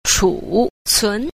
8. 儲存 – chúcún – trữ tồn (tồn trữ)
chu_cun.mp3